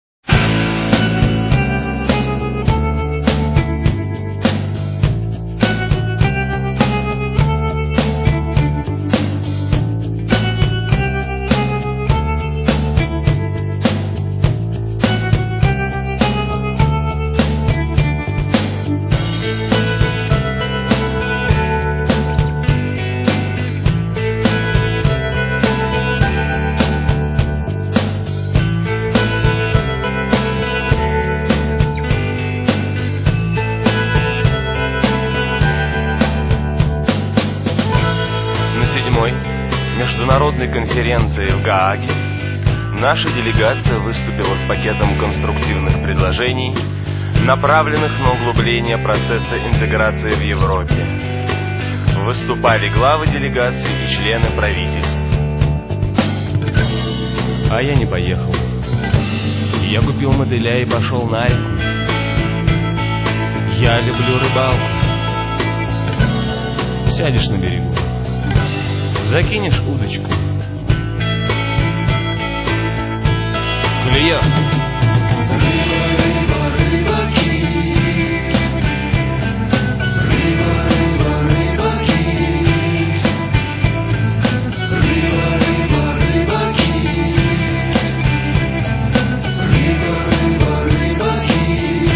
Вот такая песенка.